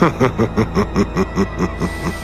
Hehehehe #2
Category: Television   Right: Both Personal and Commercial